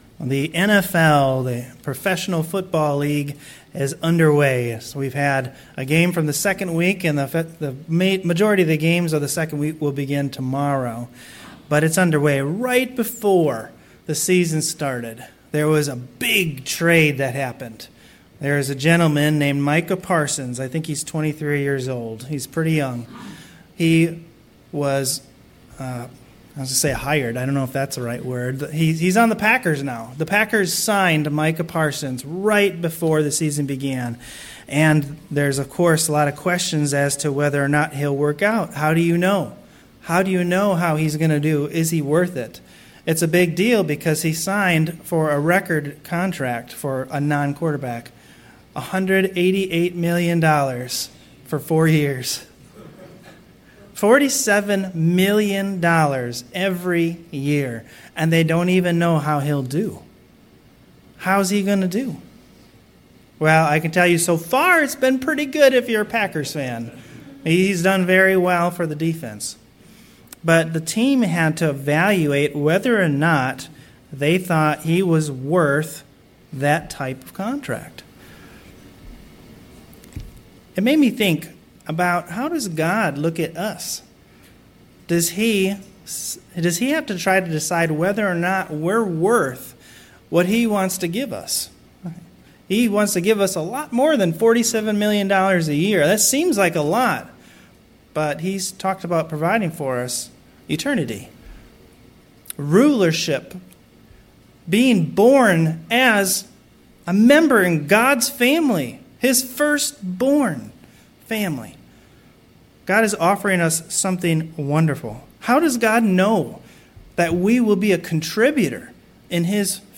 Sermons
Given in Cleveland, OH North Canton, OH